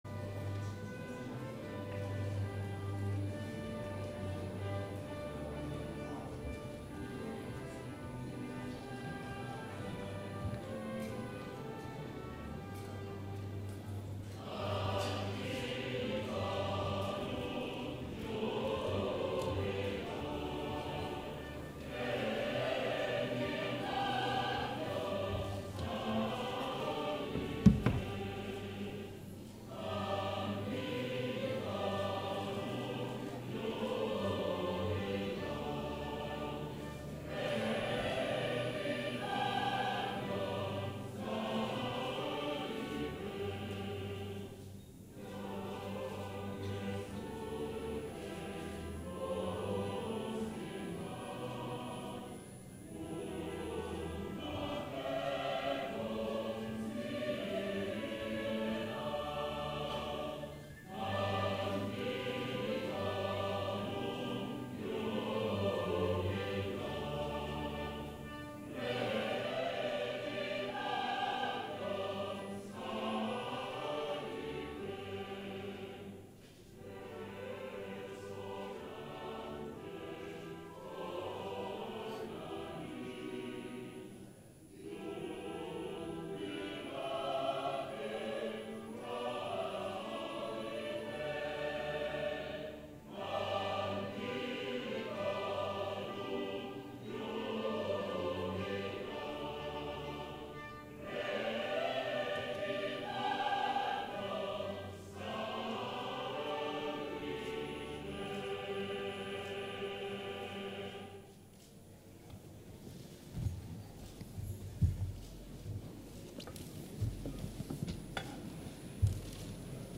Ministro de educación, rector saliente, rector entrante, secretaria general Acto de toma de posesión del rector